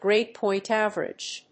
アクセントgráde pòint àverage